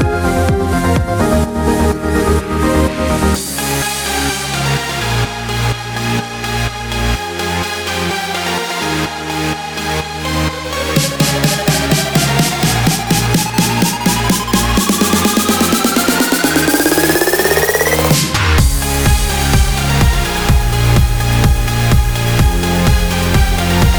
no Backing Vocals Dance 3:09 Buy £1.50